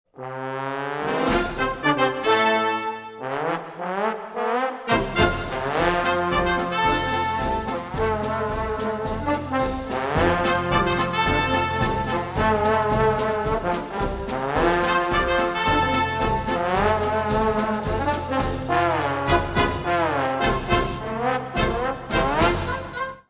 Yr 7 brass listening track 2